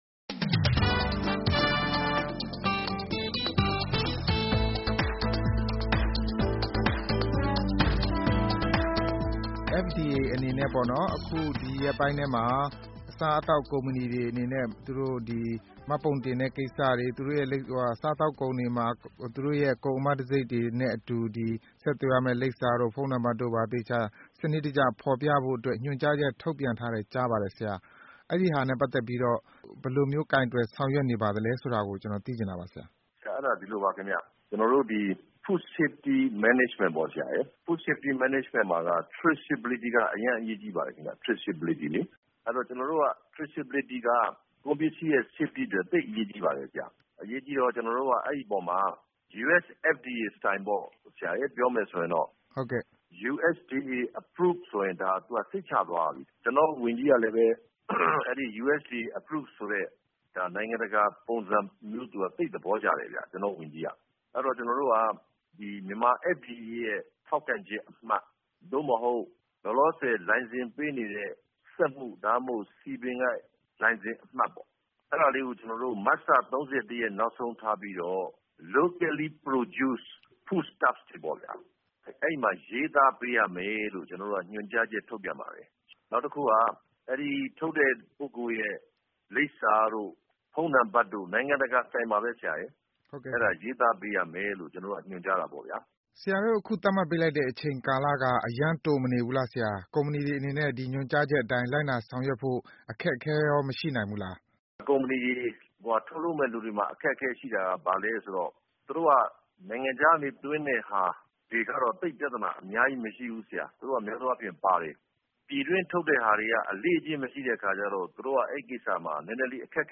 FDA ညွန်ကြားရေးမှူးချုပ် ဒေါက်တာ သန်းထွဋ်နှင့် မေးမြန်းခန်း - အပိုင်း (၁)